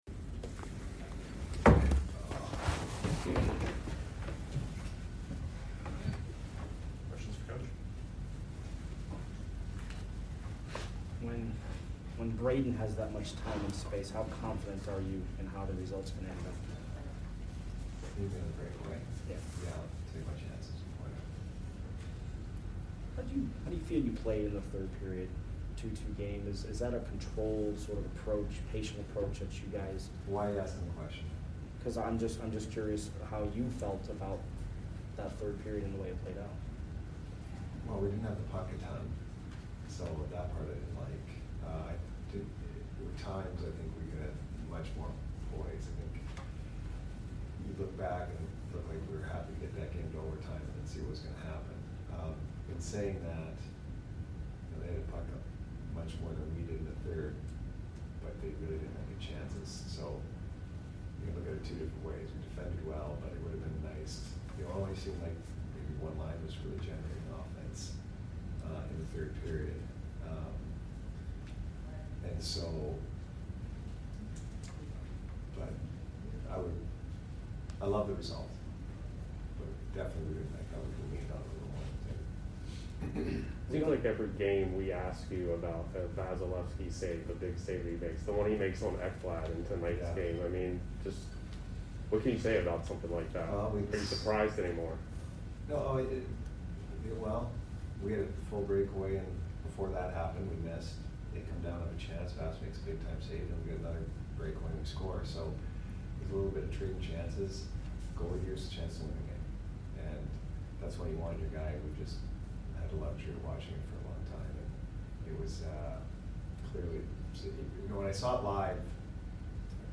Head Coach Jon Cooper Post Game Vs FLA 11/13/21